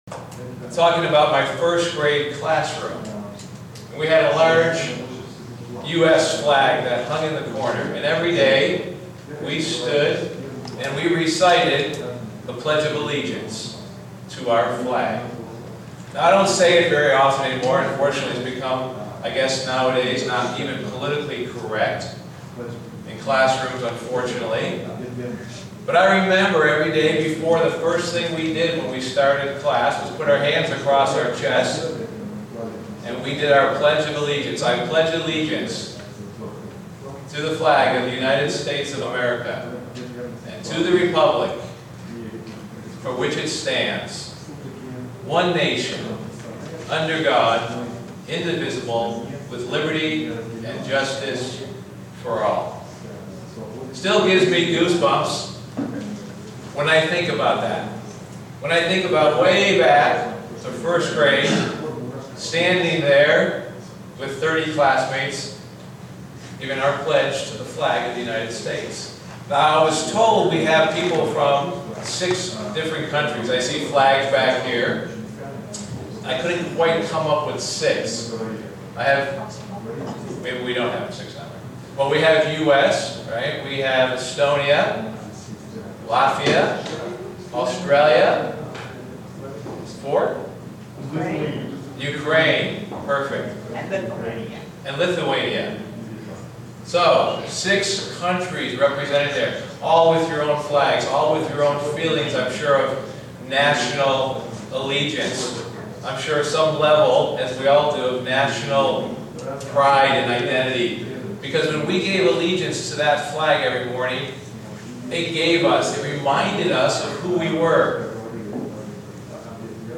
Given in Buffalo, NY Elmira, NY
Print Where our citizenship is now and where it will be forever for those who are chosen by God. sermon Studying the bible?